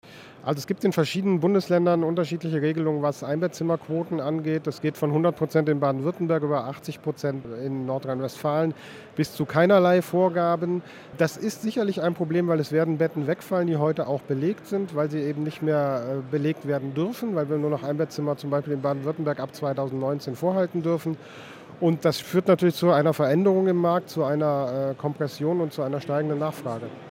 O-Ton von der EXPO REAL: Sind Pflegeimmobilien ein sicheres Anlagemodell?